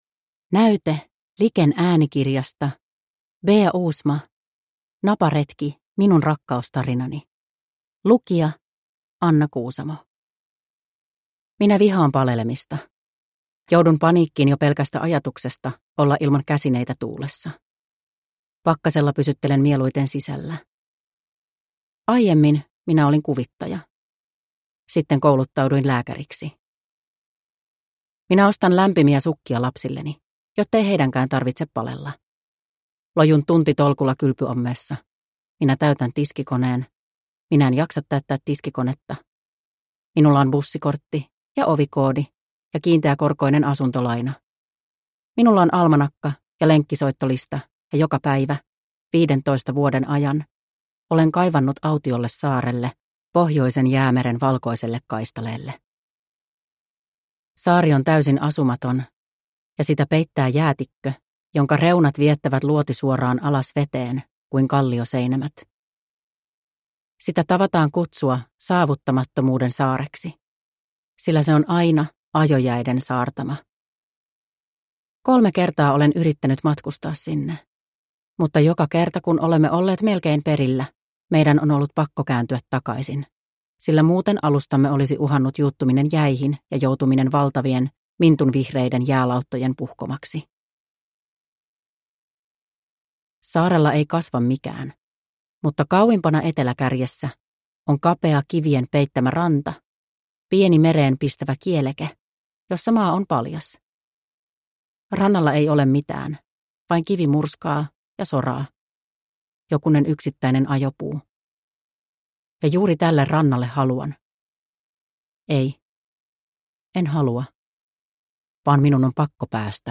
Naparetki - minun rakkaustarinani – Ljudbok